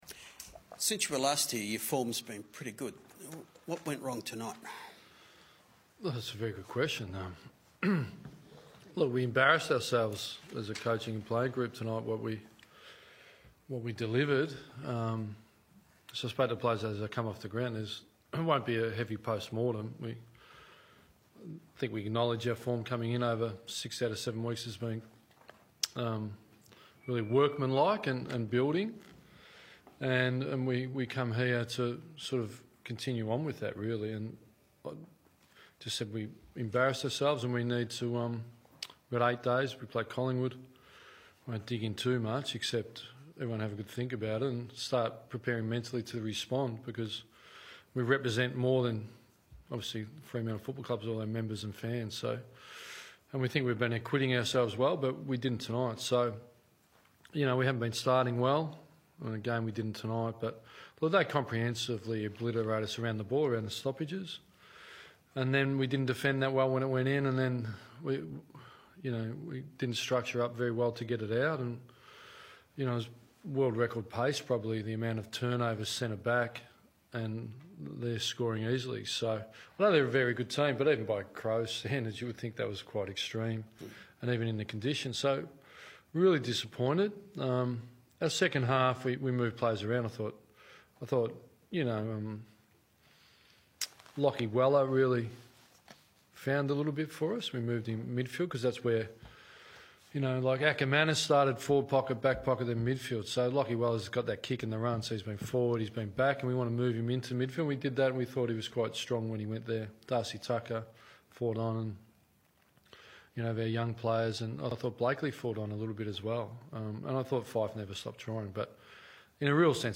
Ross Lyon chats to the media after Freo's clash over the Crows.